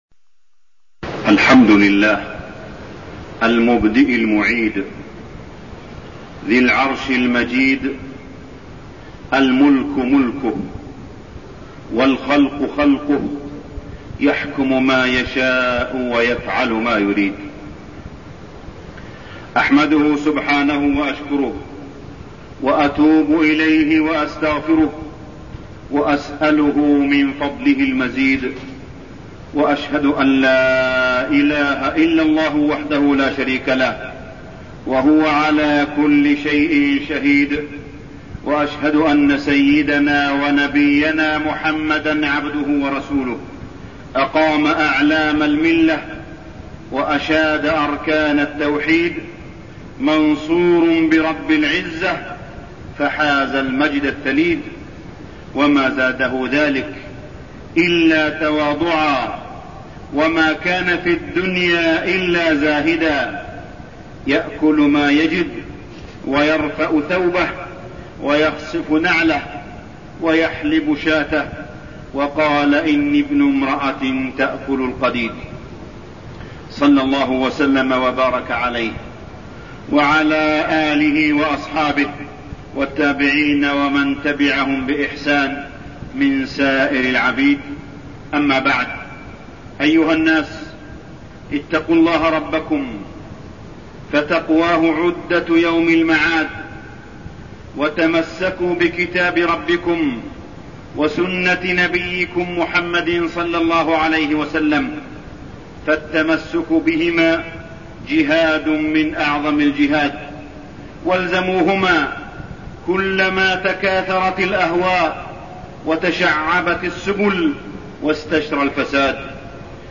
تاريخ النشر ٢ ذو القعدة ١٤١٣ هـ المكان: المسجد الحرام الشيخ: معالي الشيخ أ.د. صالح بن عبدالله بن حميد معالي الشيخ أ.د. صالح بن عبدالله بن حميد مفهوم الزهد